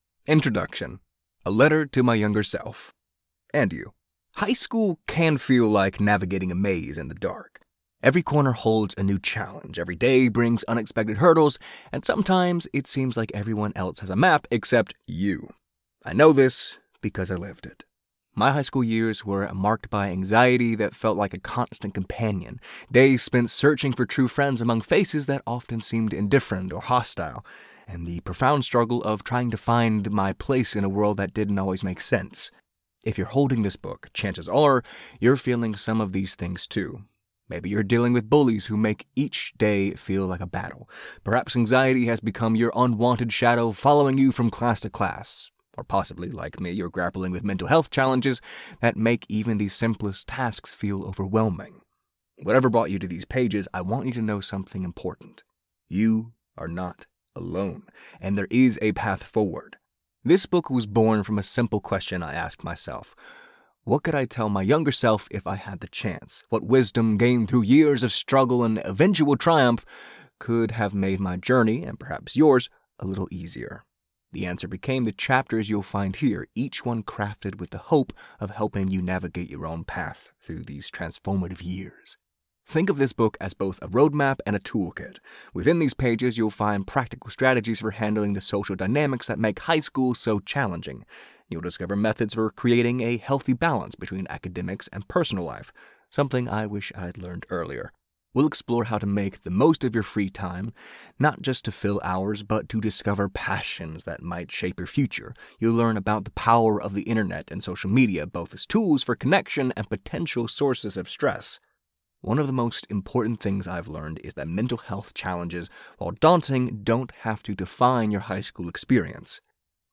DIY-Demo-Audiobook-Nonfiction-Self-Help.mp3